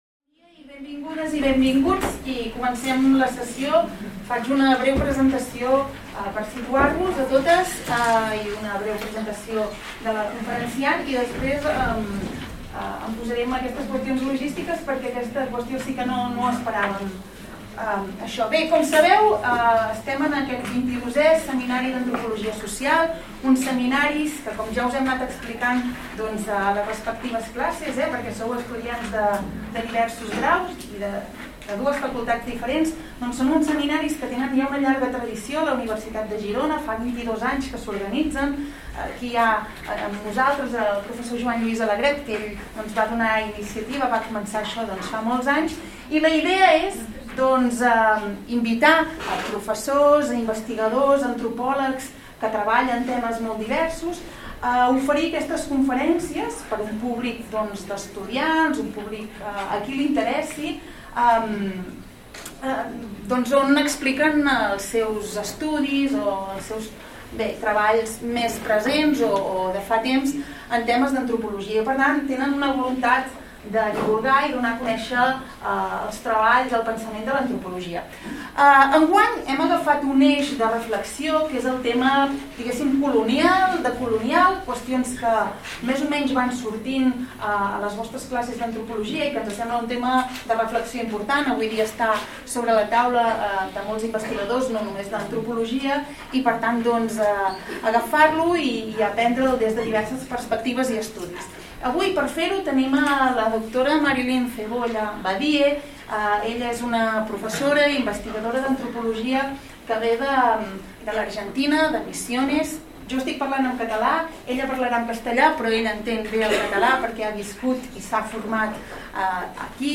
La conferència